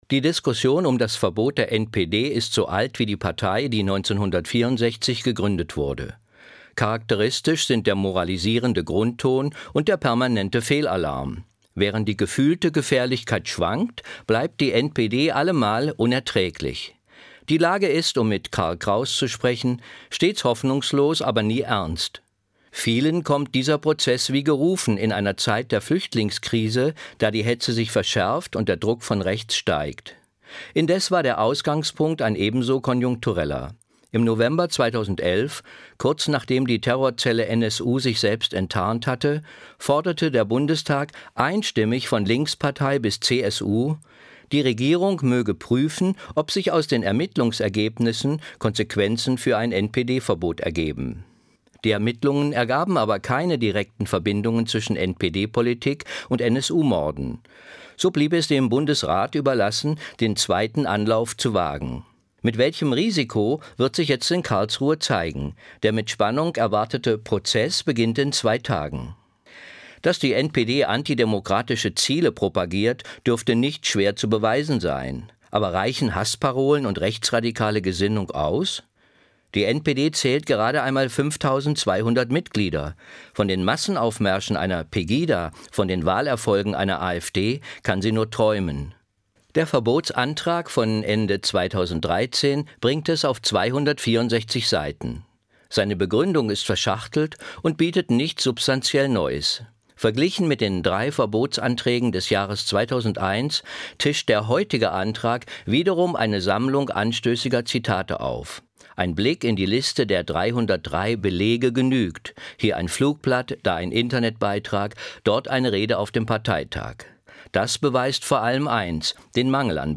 Essay Gedanken zur Zeit, NDR Kultur, 28.